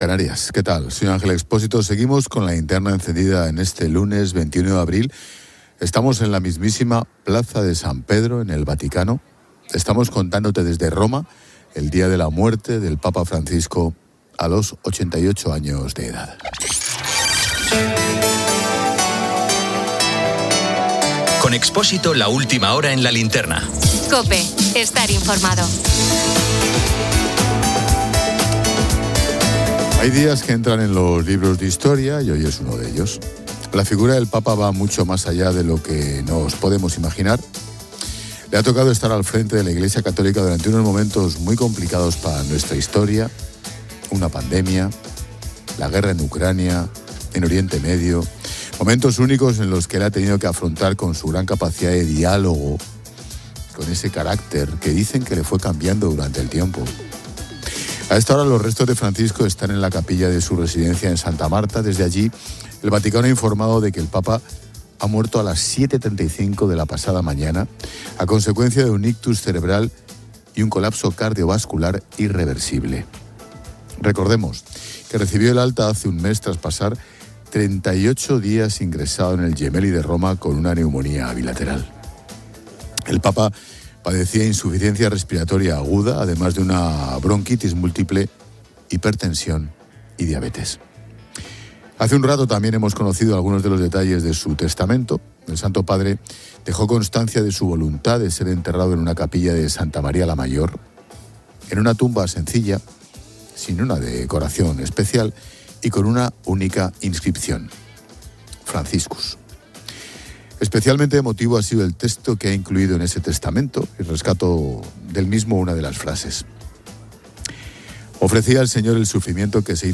Estamos en la mismísima Plaza de San Pedro en el Vaticano. Estamos contándote desde Roma el día de la muerte del Papa Francisco a los 88 años de edad.